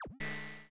Custom synthesized sounds — no generic notification bleeps here:
Error/Attention
▶ Play Dramatic sweep — something went sideways
error.wav